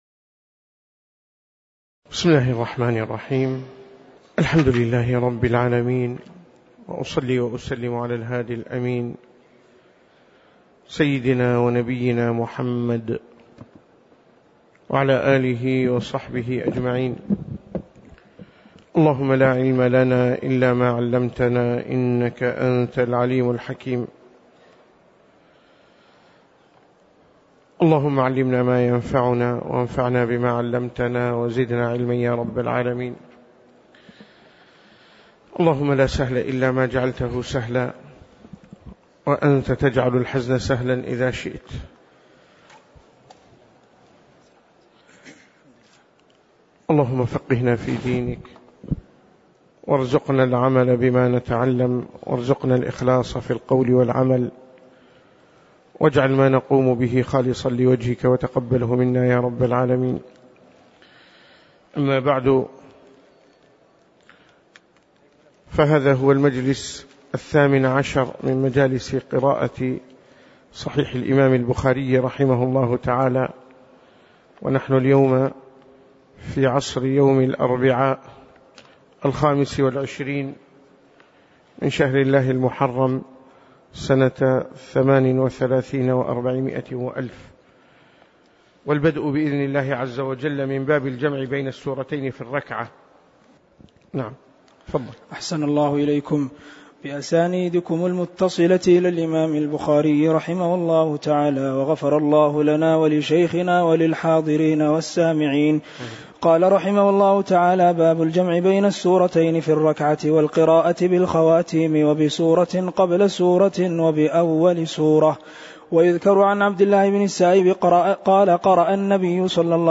تاريخ النشر ٢٥ محرم ١٤٣٨ هـ المكان: المسجد النبوي الشيخ